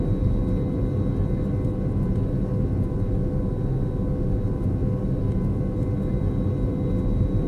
CosmicRageSounds / ogg / general / combat / aircraft / eng.ogg